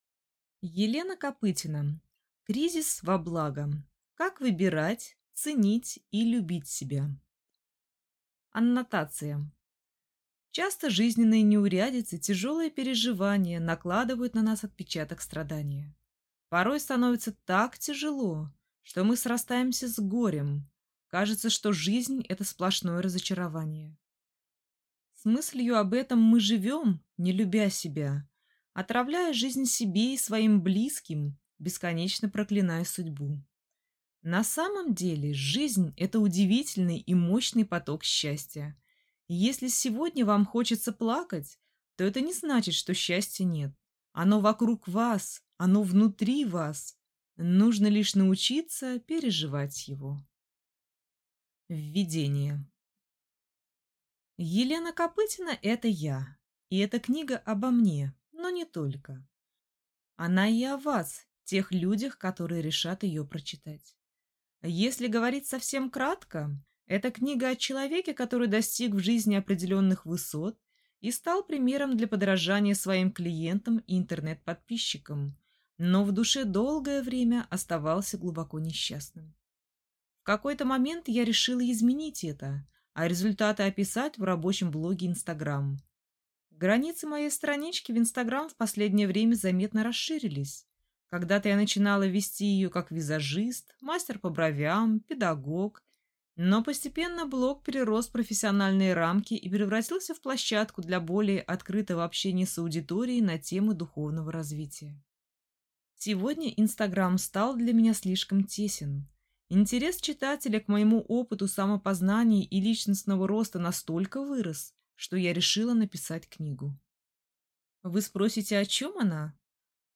Аудиокнига Кризис во благо: как выбирать, ценить и любить себя | Библиотека аудиокниг